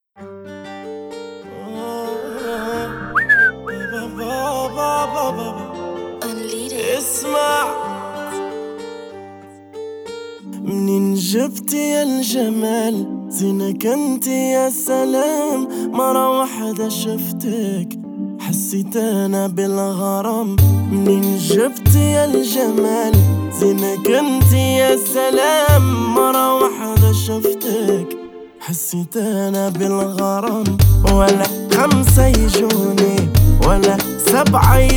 Жанр: Поп
# Arabic Pop